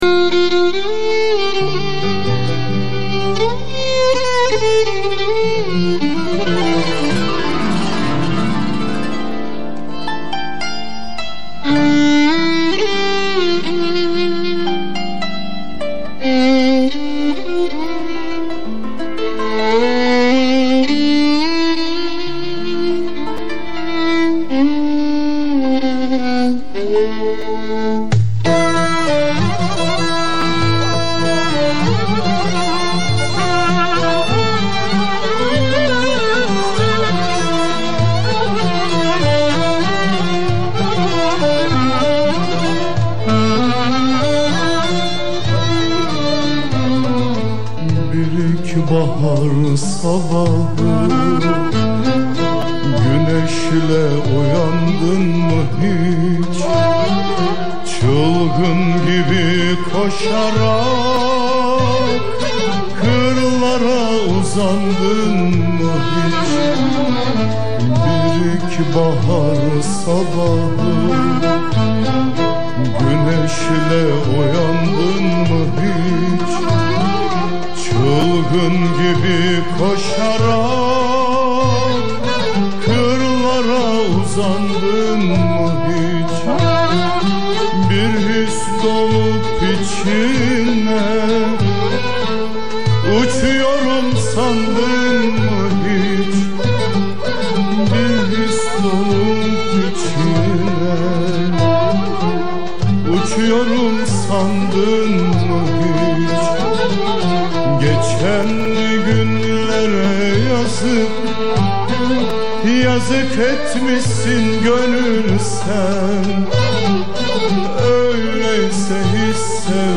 Eser: Bir ilkbahar sabahı güneşle uynadın mı hiç Bestekâr: Erdoğan Berker Güfte Sâhibi: Bekir Mutlu - Doktor, Şair Makam: Nihavend Form: Şarkı Usûl: Düyek Güfte: - Kaynak: Sanat Müziği Notaları Diğer Bilgiler: Arşiv No: 2079 -